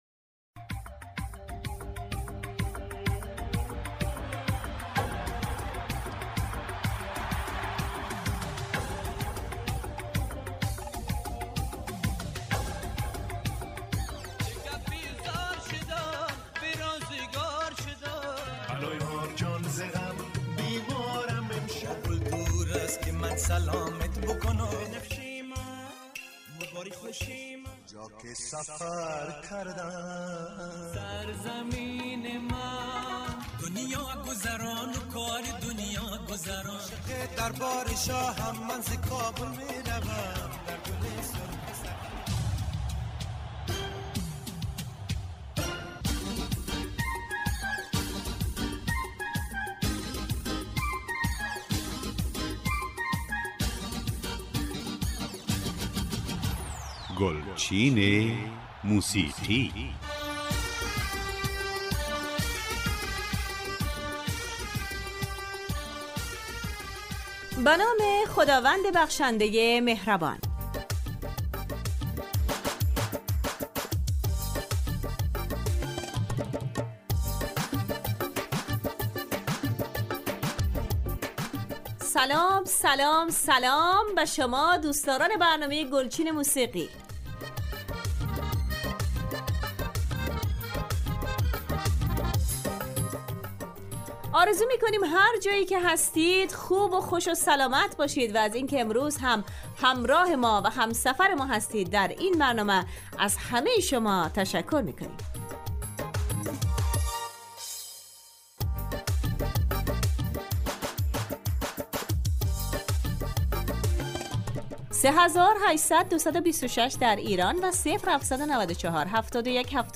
برنامه ای متنوع با پخش آهنگهای جدید محلی و پاپ فارسی